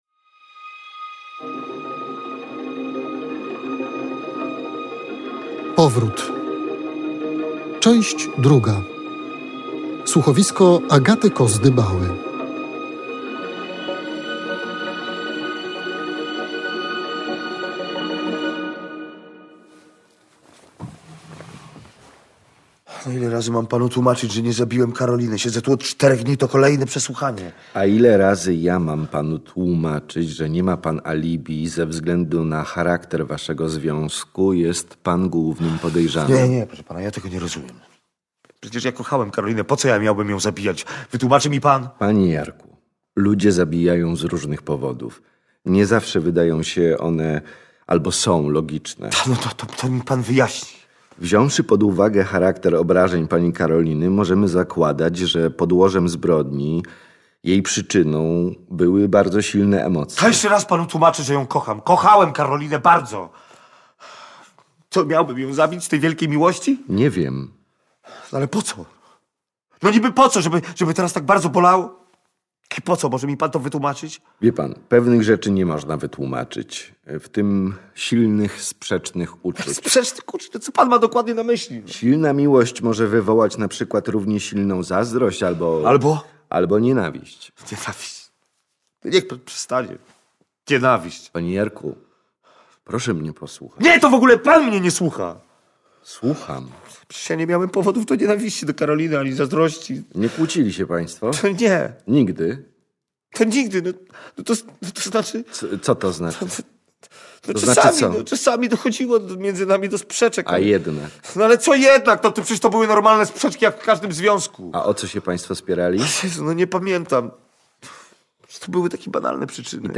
Czy zabójstwo młodej kobiety to pojedynczy przypadek, czy też punkt w ciągu tragicznych wydarzeń. Kilka podpowiedzi znajdą Państwo w drugim odcinku słuchowiska „Powrót”.